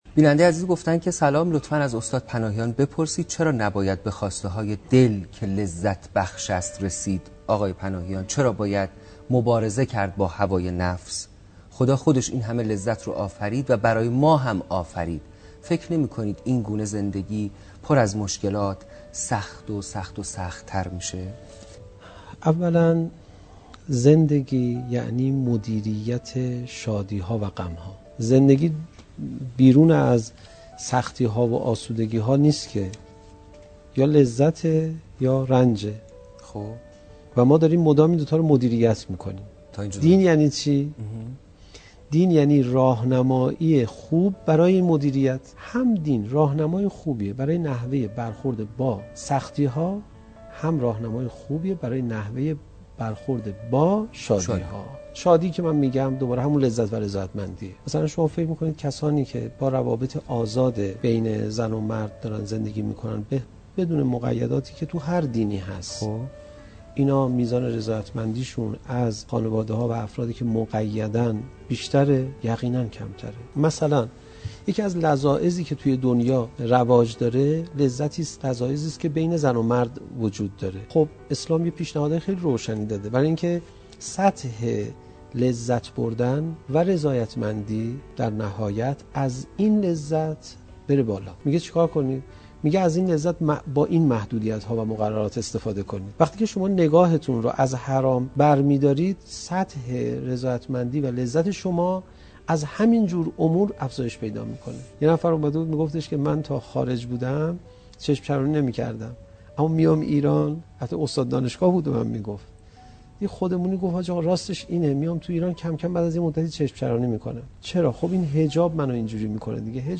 یکی از سؤالاتی که عمدتاً از سوی جوانان امروزی مطرح می‌شود این است که خدا این همه لذت را برای ما آفریده است چرا استفاده نکنیم؟ حجت‌الاسلام والمسلمین علیرضا پناهیان، خطیب کشورمان در این زمینه پاسخ می‌دهد.